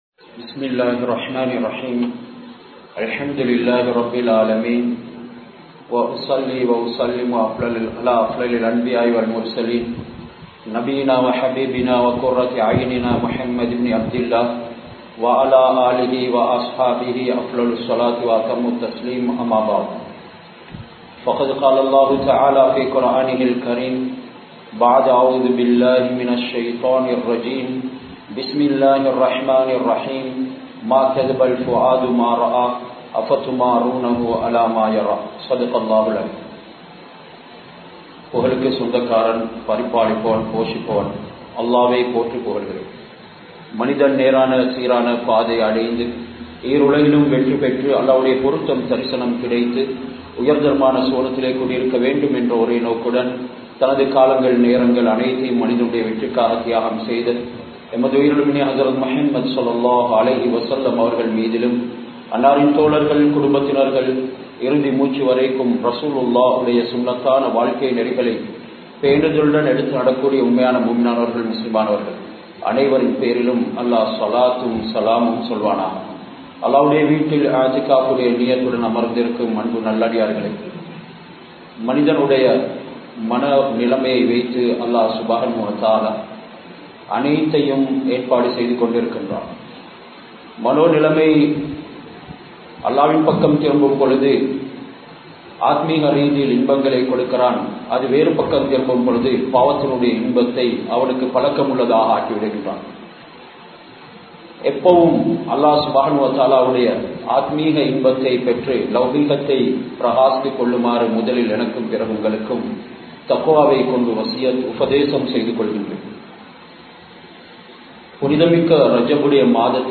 Naam Tholum Tholuhaien Nilai Enna? (நாம் தொழும் தொழுகையின் நிலை என்ன?) | Audio Bayans | All Ceylon Muslim Youth Community | Addalaichenai
Kandy, Bahirawakanda Jumua Masjidh